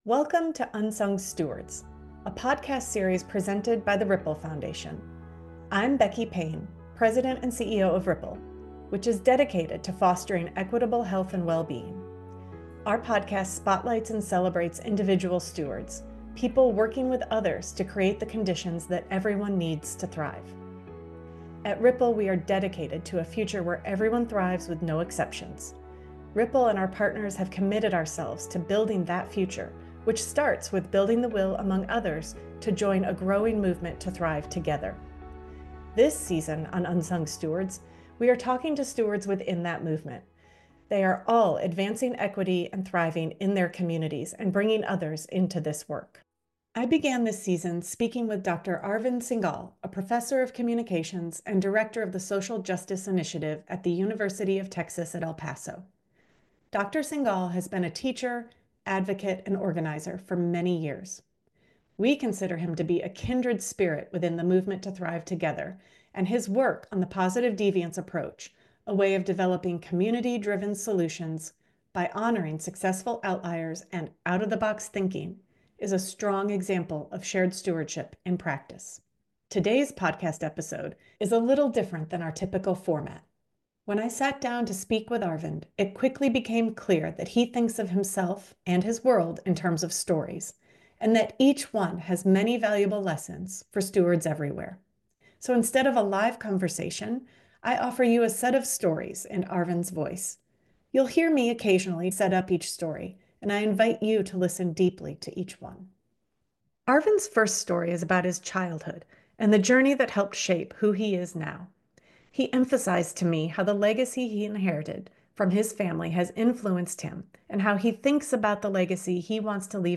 Join us for a conversation